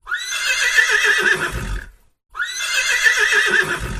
Horse Whinny
Horse Whinny is a free animals sound effect available for download in MP3 format.
038_horse_whinny.mp3